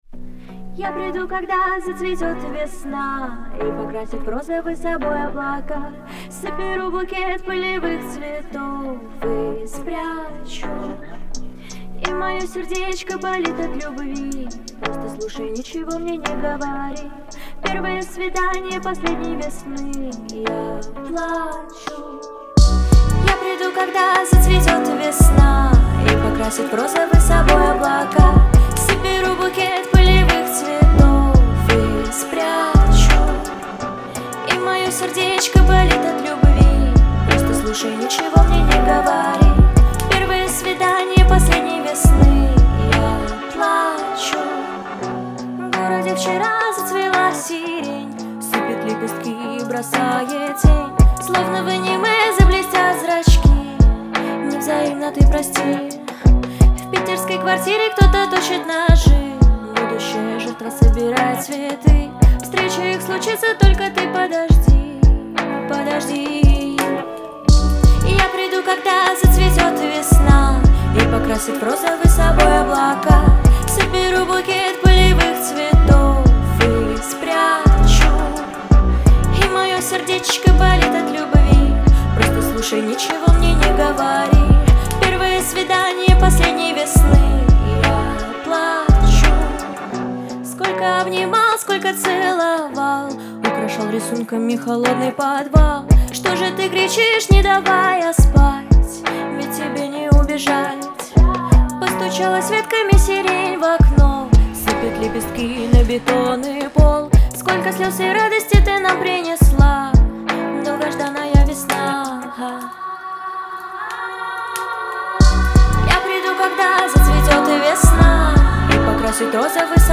нежная и трогательная песня
исполненная в жанре поп с элементами инди.